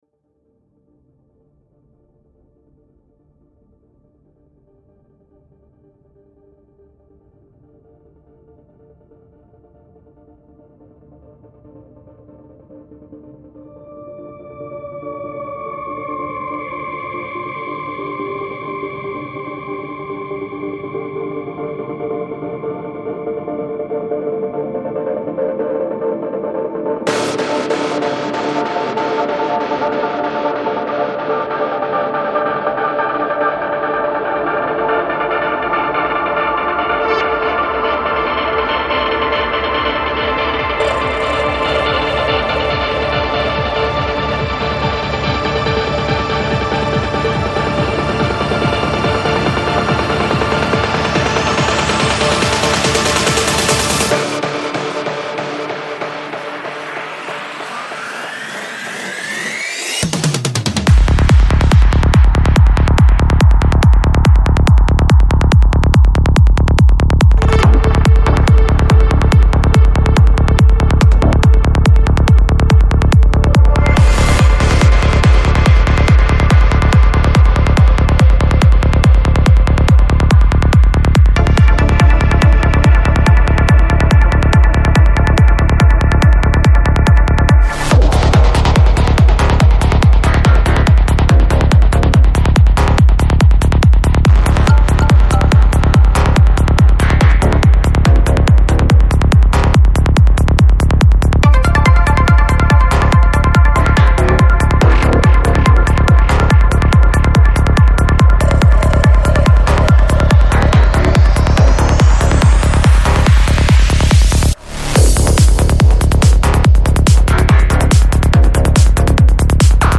Жанр: Dance
Psy-Trance